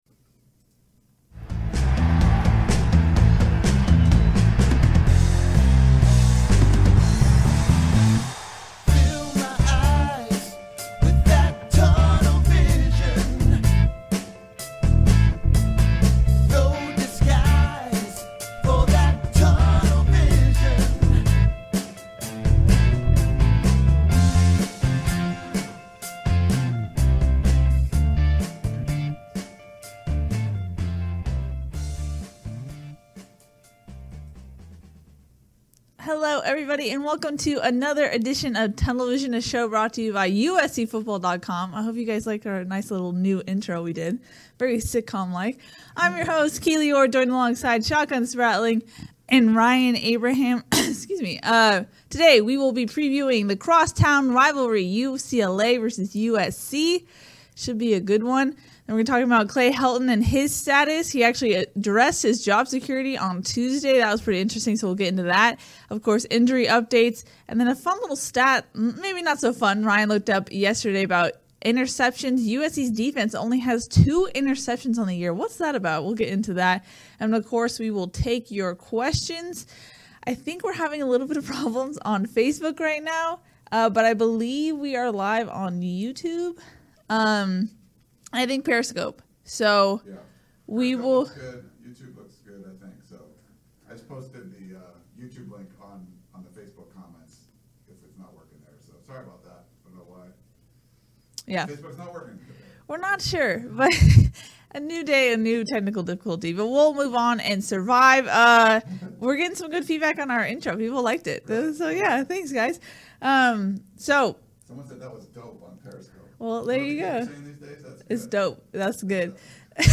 USC fans are much more interested in some of the problems this Trojan football team has been having and of course what the future of USC head coach Clay Helton is. This is the podcast version of our Facebook/YouTube/Periscope live show (with video).